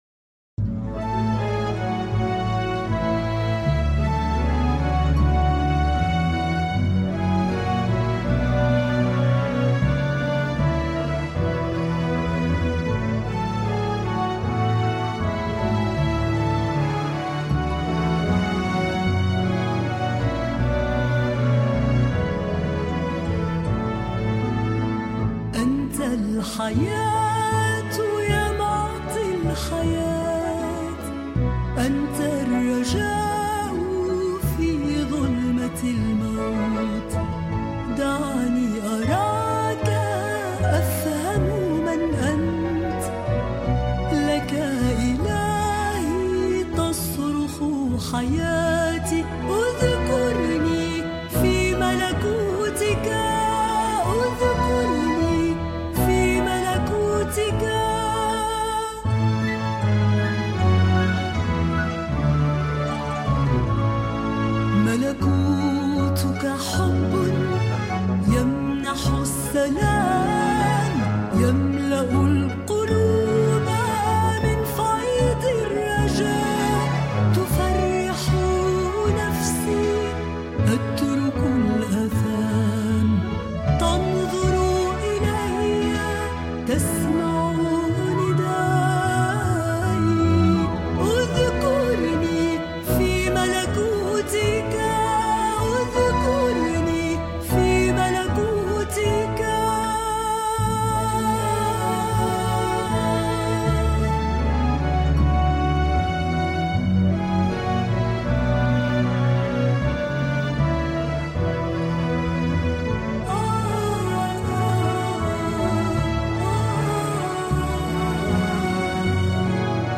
ترانيم